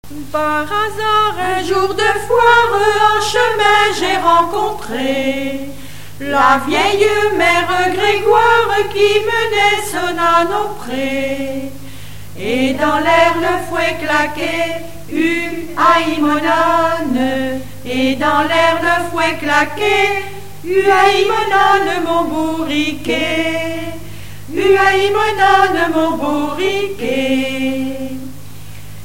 Informateur(s) Chorale locale
Genre laisse
Chansons apprises à l'école